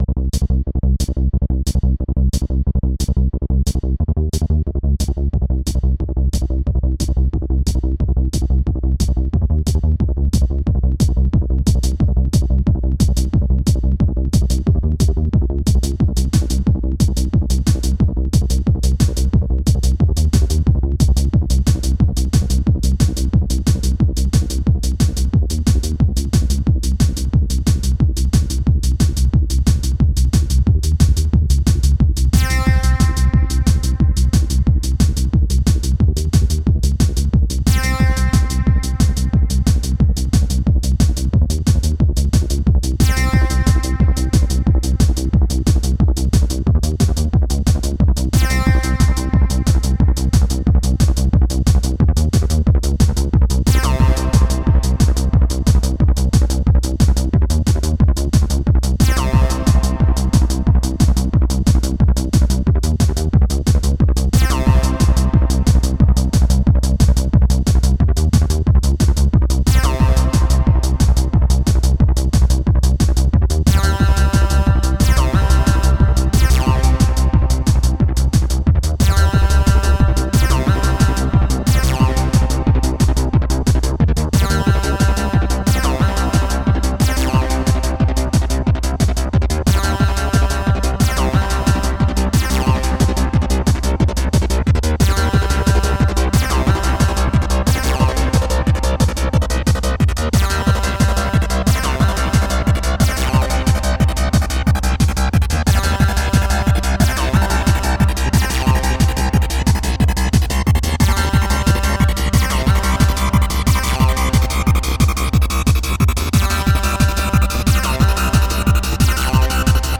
Acid Trance
180bpm